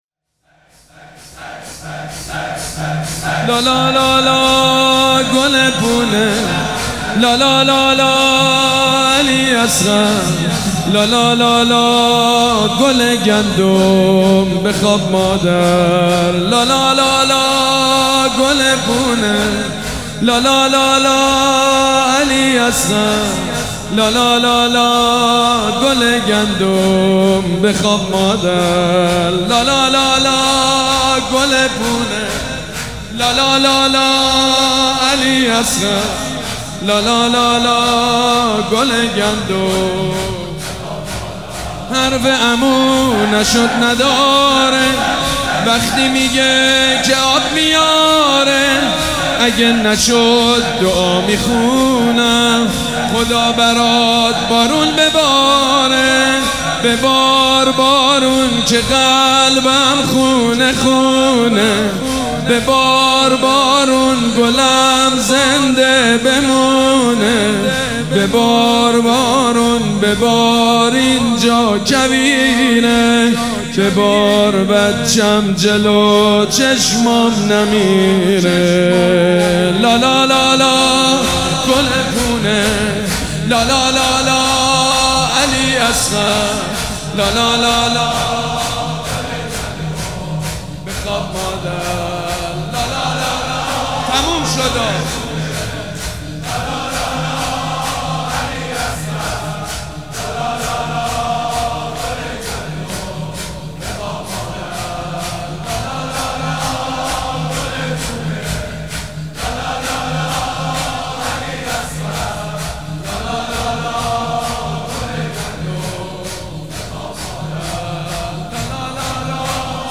مداحی شب هفتم محرم